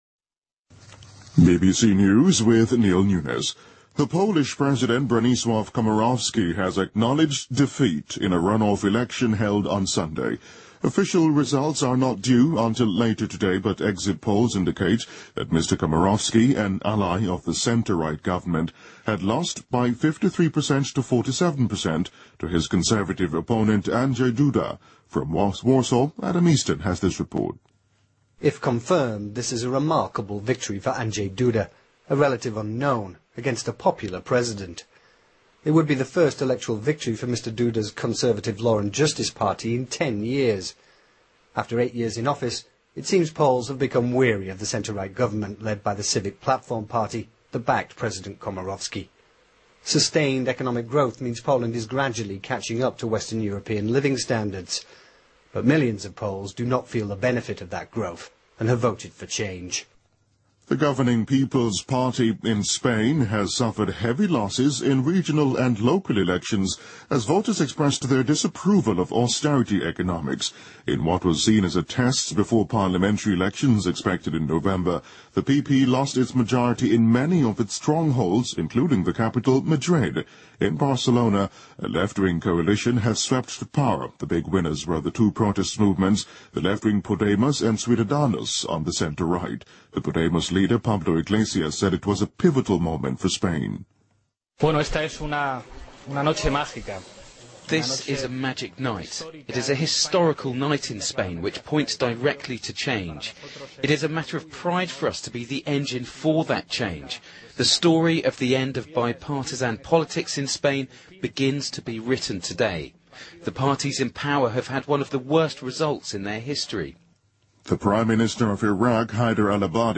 BBC news,法国电影流浪的迪潘在戛纳电影节获得最佳影片金棕榈奖